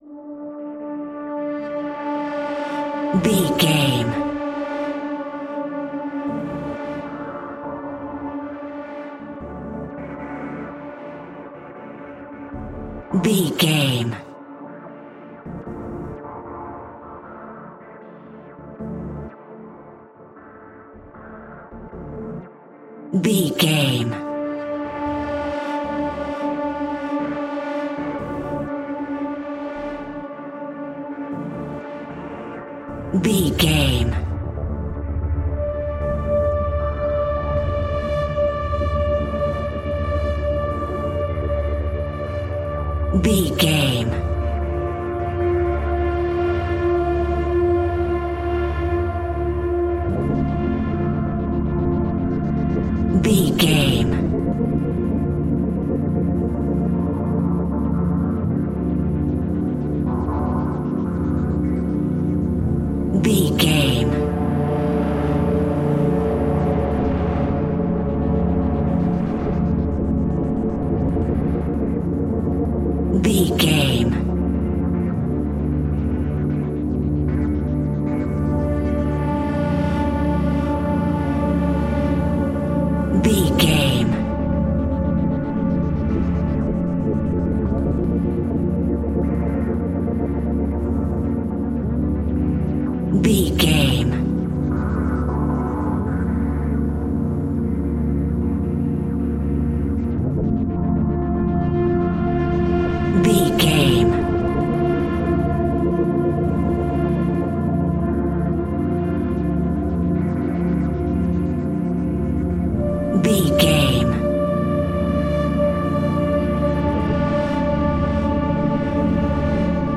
Horror Scary Pads.
Atonal
D
ominous
eerie
strings
synthesiser